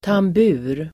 Uttal: [tamb'u:r]